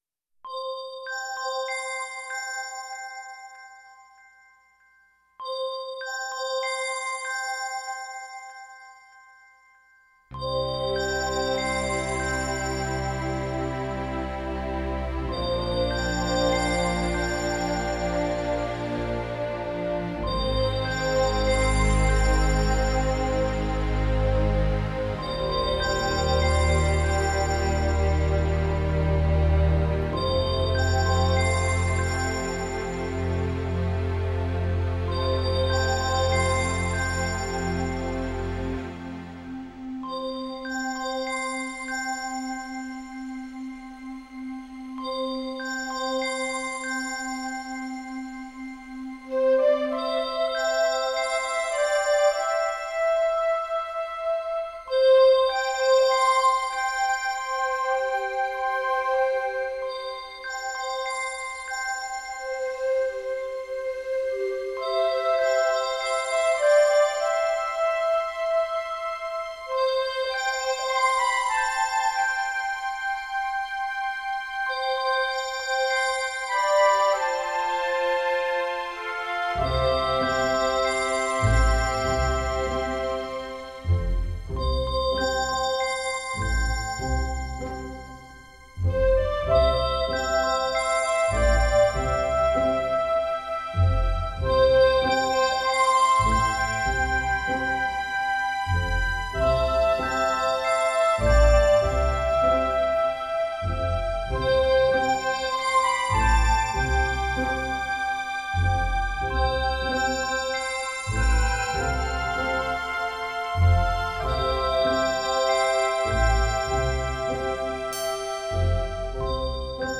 Стиль: NewAge, Christmas